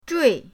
zhui4.mp3